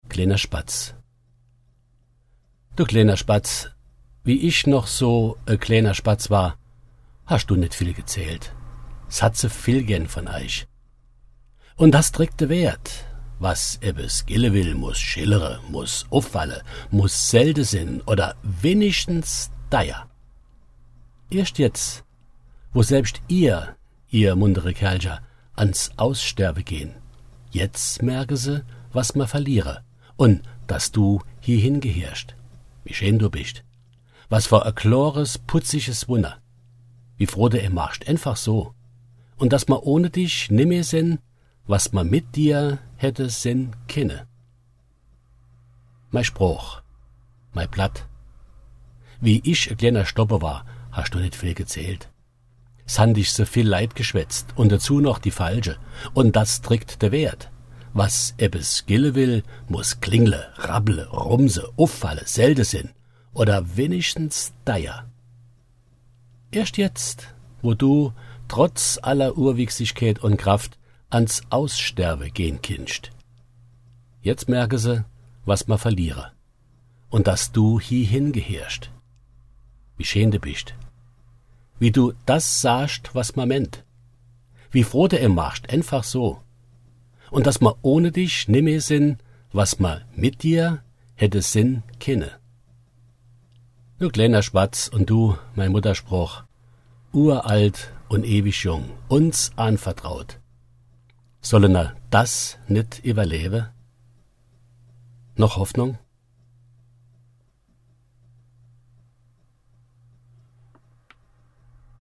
Mundart • Es Haisje Glääner Schbadds Kleiner Spatz -1992 Du glääner Schbadds!